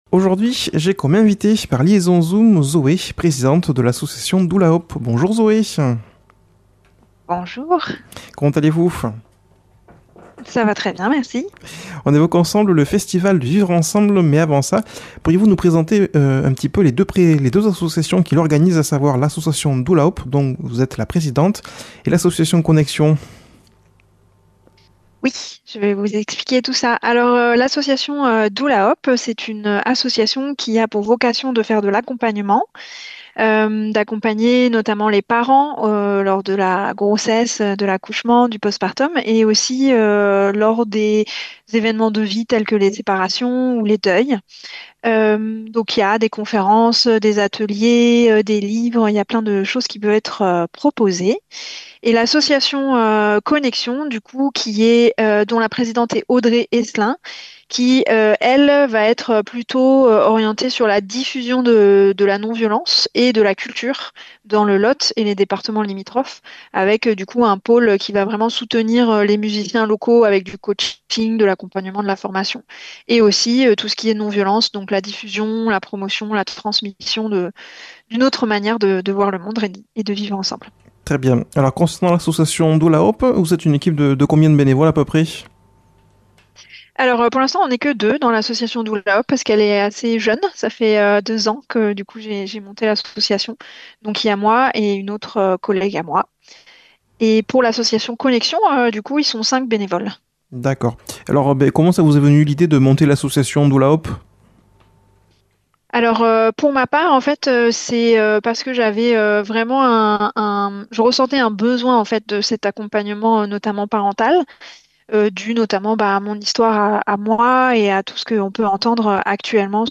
Une émission présentée par
Présentateur